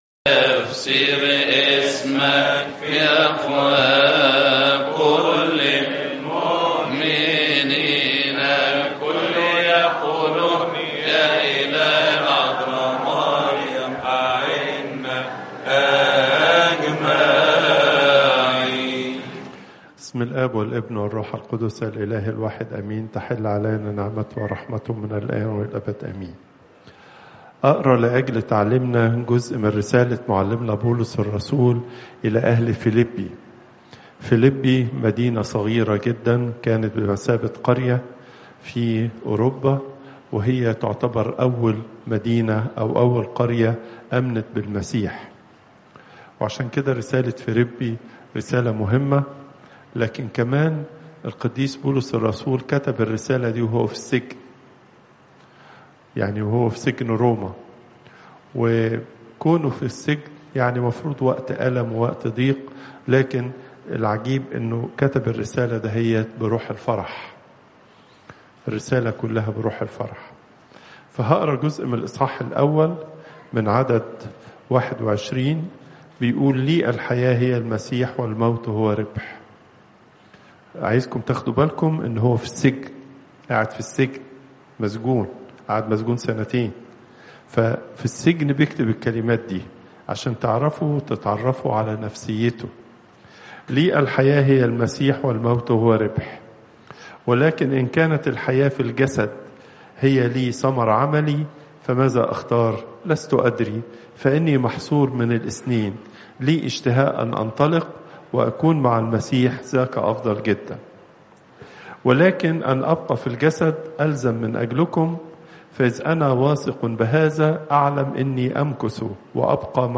Pope Tawdroes II Weekly Lecture